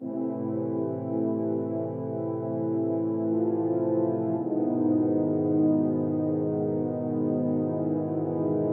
爵士萨克斯循环
描述：爵士乐
标签： 110 bpm Jazz Loops Brass Loops 1.47 MB wav Key : C
声道立体声